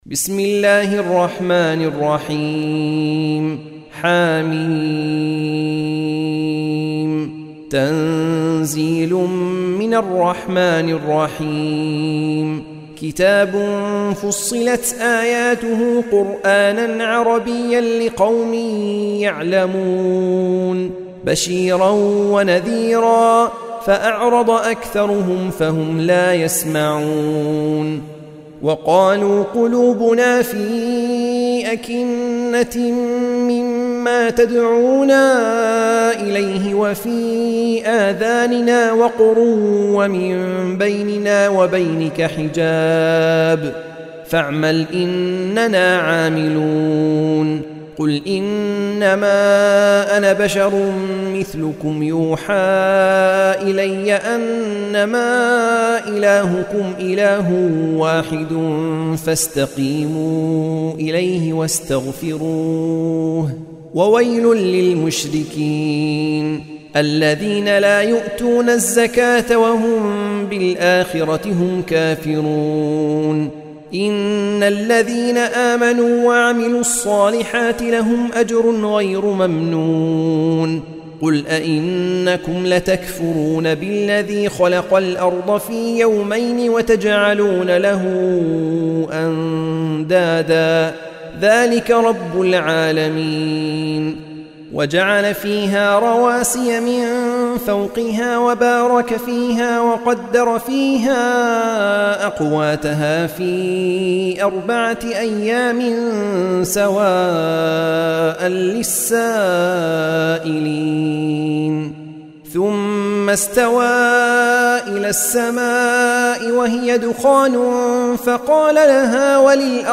سورة فصلت | القارئ